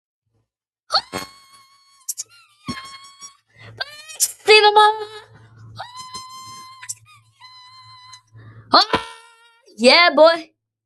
Ahhhhhhhhhhh Sound Button - Free Download & Play